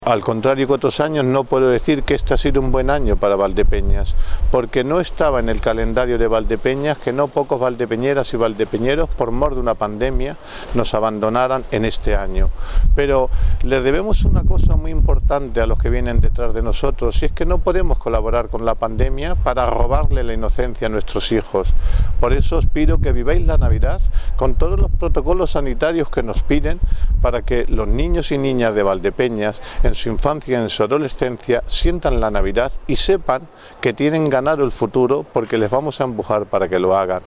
El alcalde de Valdepeñas, Jesús Martín, hace en su tradicional mensaje de Navidad un llamamiento a la ciudadanía para que se cumplan todas las medidas de seguridad en estas fiestas.